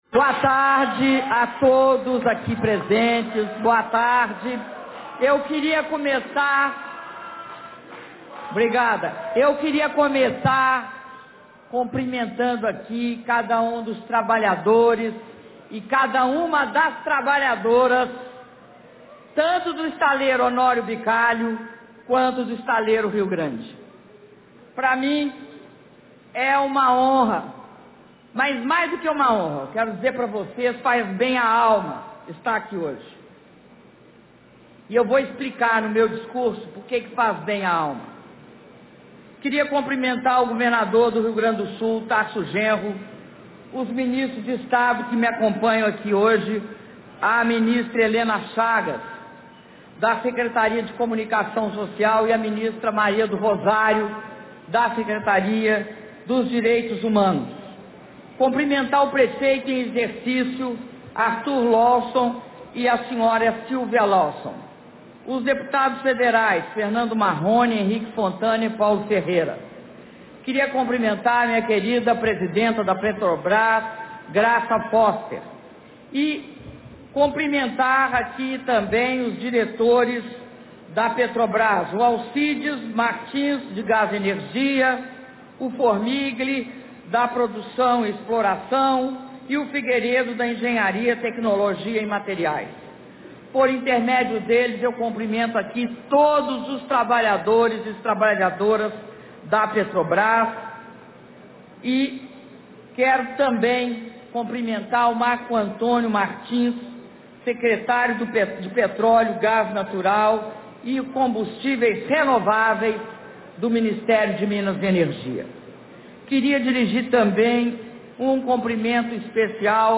Áudio do discurso da Presidenta da República, Dilma Rousseff, na cerimônia de conclusão da Plataforma P-58 - Rio Grande/RS